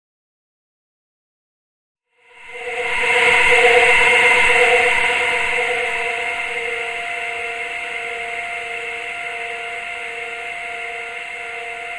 氛围 " 苛刻的静电
描述：取自旧的个人录音机。它是在十多年没有改变的手表电池上运行，导致播放只是严酷的静电。如果你仔细聆听，你也会听到乱码的数字噪音。 使用的设备：TASCAM DR40记录器使用的软件：Audacity 2.0.3
标签： 回路 发痒 静态 爬行 高铈 苛刻 噪声
声道立体声